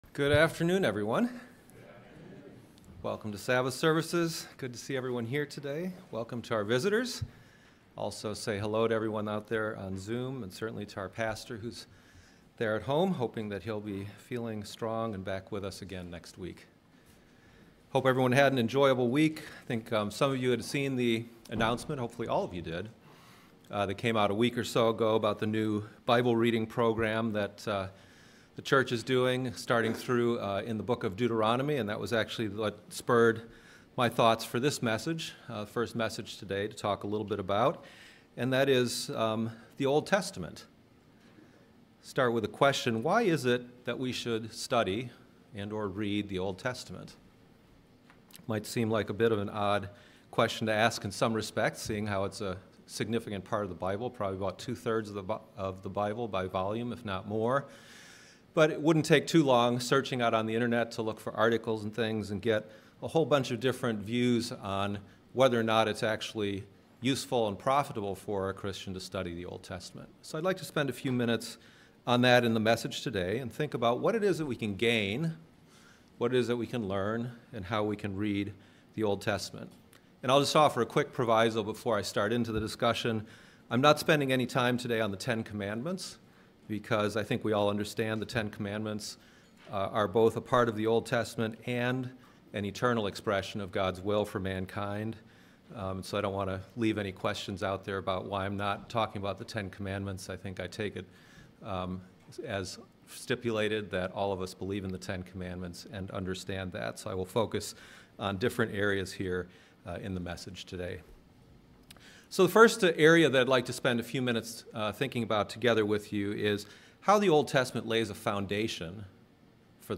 sermon_why_should_we_study_the_old_testament.mp3